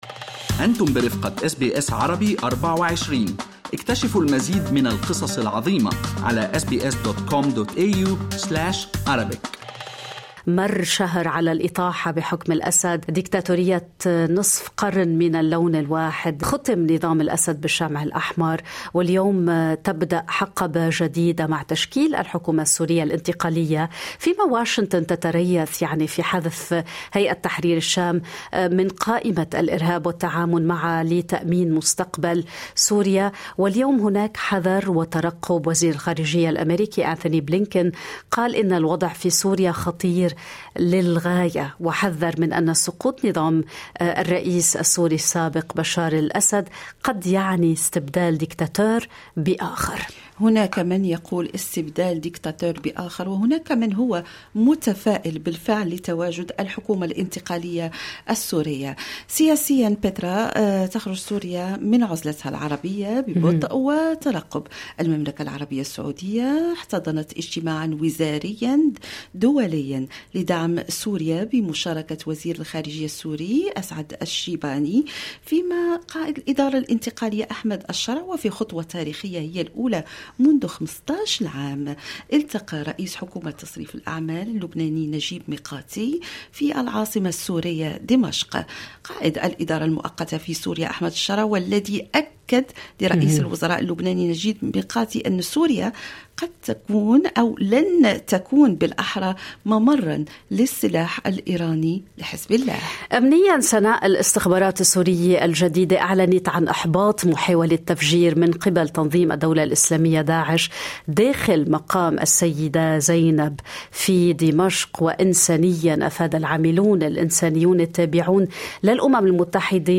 الاجابة مع الكاتبة والمدافعة عن حقوق الإنسان